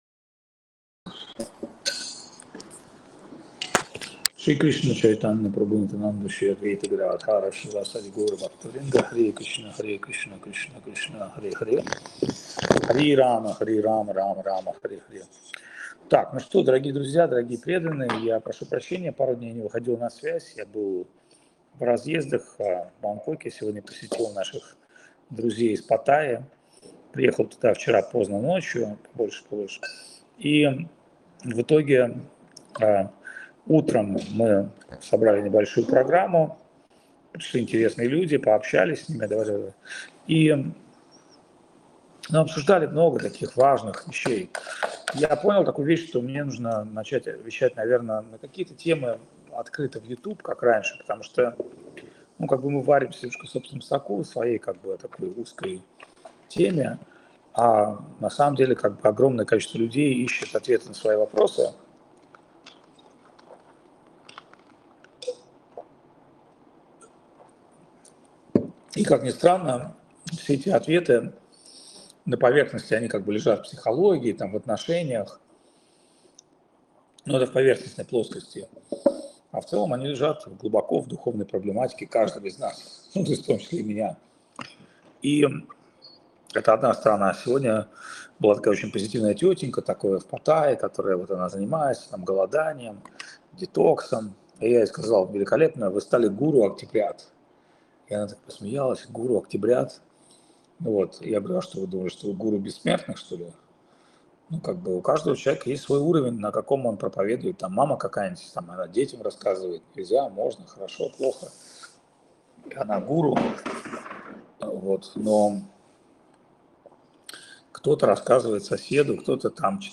Место: Чиангмай (1134) Таиланд
Лекции полностью